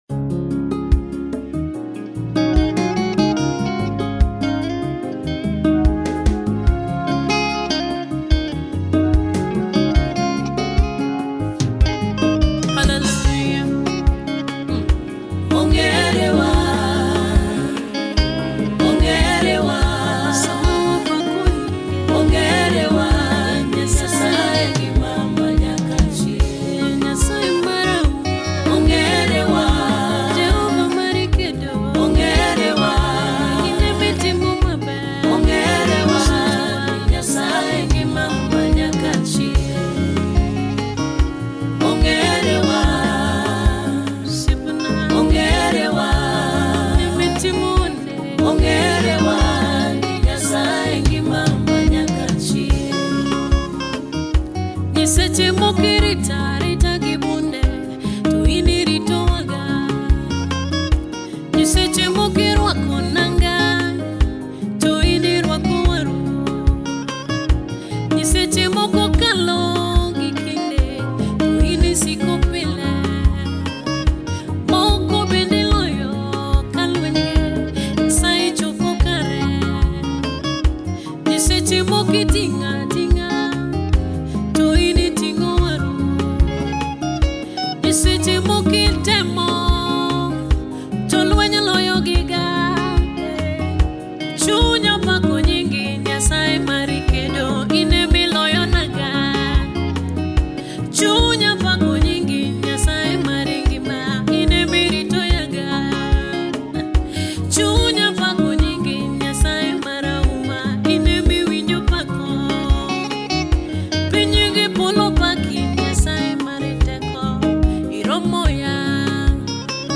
Universal Gospel
The renowned gospel music minister